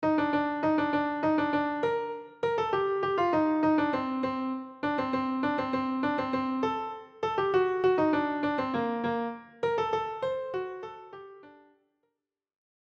He then uses that whole motif to set up the same structure. Bars 5-8 (B) are a repetition of bars 1-4 (A) but with changes in pitch.
Playing the motif twice is just enough times before we’re pulled forward in a new direction (C).